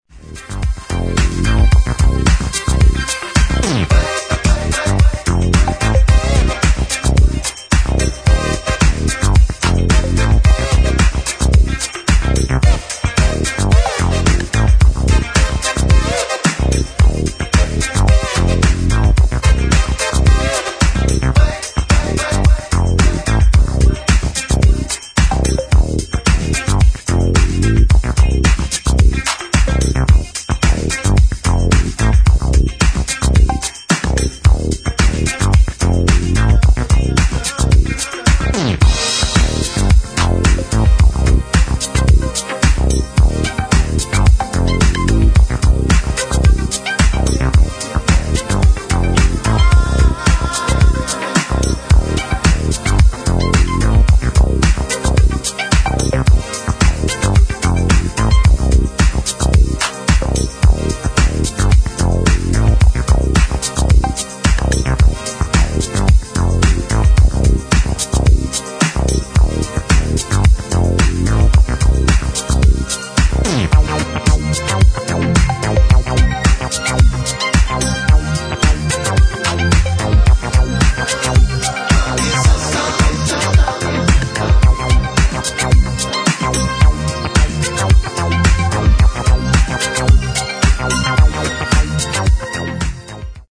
[ DISCO ]